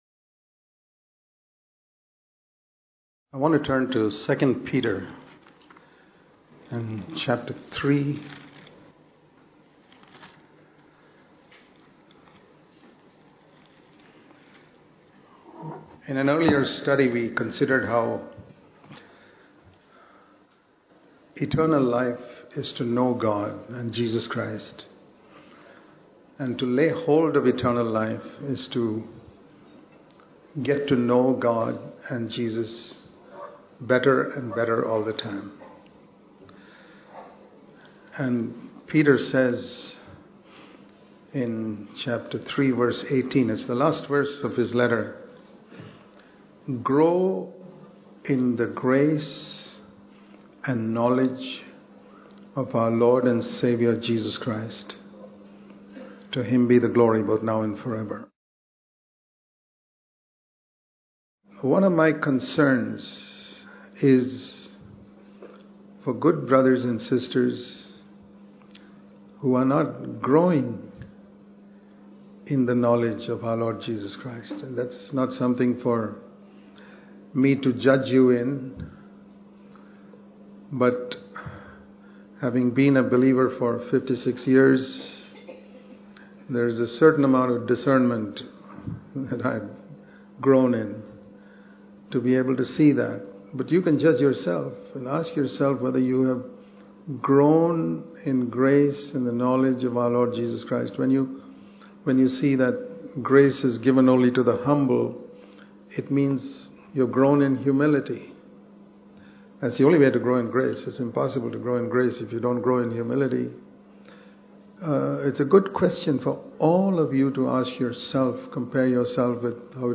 Message spoken
at Christian Fellowship Church